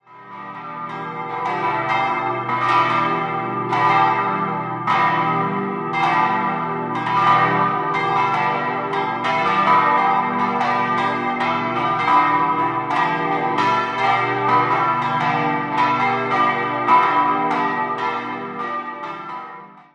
Die katholische Pfarrkirche Heilige Familie wurde im Jahr 1914 eingeweiht und besitzt einen sehr nüchtern ausgestatteten Innenraum. Idealquartett: h°-d'-e'-g' Die Glocken wurden 1930 von der Gießerei Hamm (Staad bei Rorschach) gegossen.